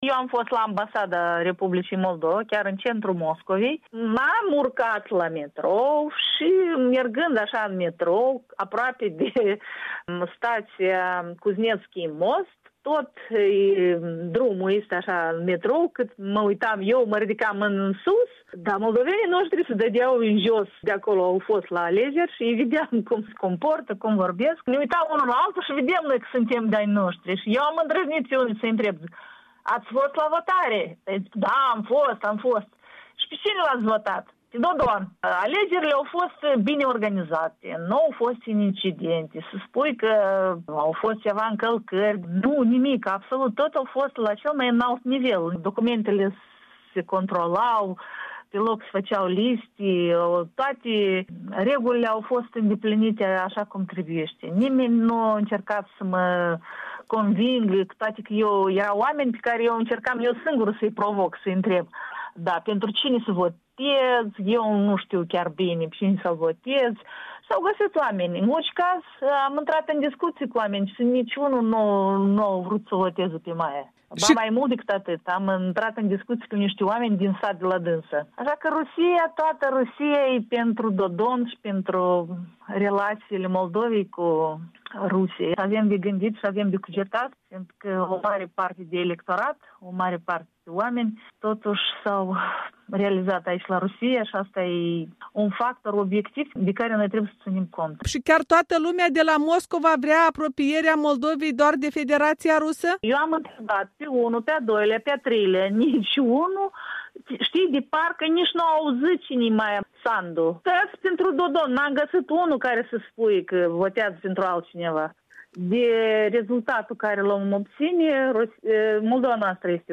...spune o moldoveancă stabilită de 25 de ani la Moscova.
în dialog